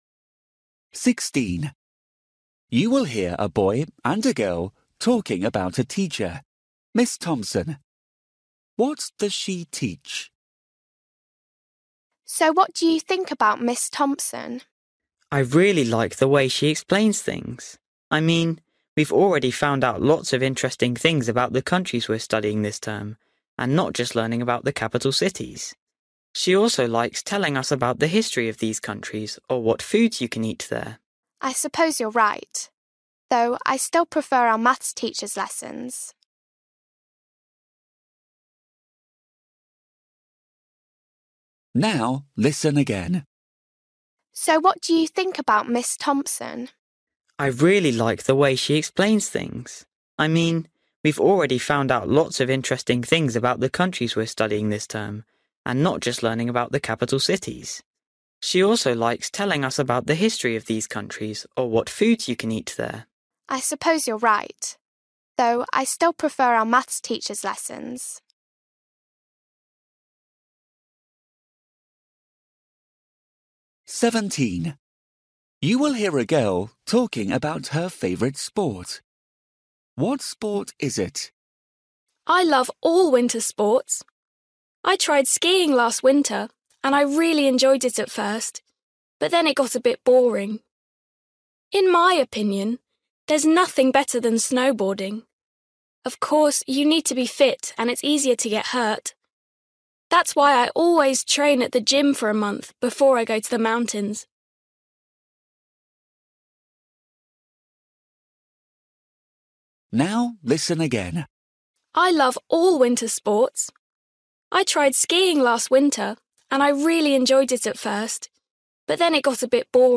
Listening: everyday short conversations
16   You will hear a boy and a girl talking about a teacher, Ms Thompson.
17   You will hear a girl talking about her favourite sport.
18   You will hear a boy talking with his mother.
19   You will hear a football coach talking to one of the players.
20   You will hear two friends talking about what they will do tomorrow.